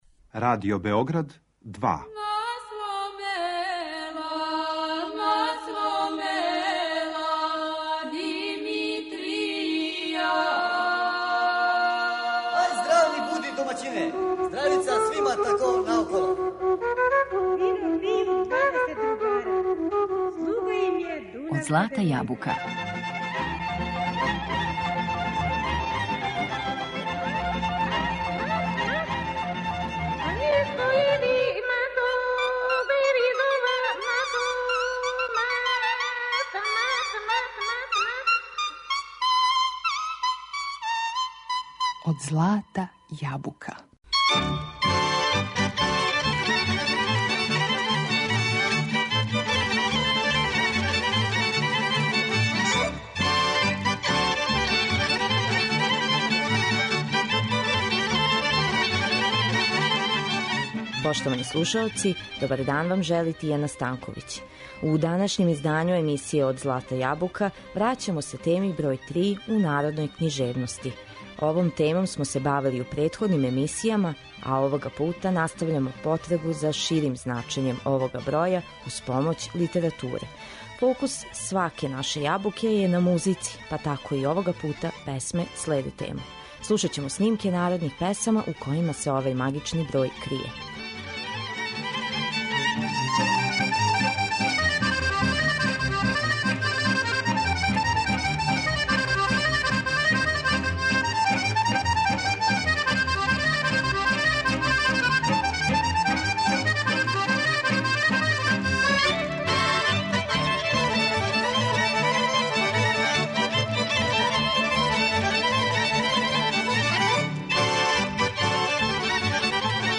Фокус сваке наше Јабуке је на музици, па тако и овога пута песме следе тему. Слушаћемо снимке народних песама у којима се овај магични број крије.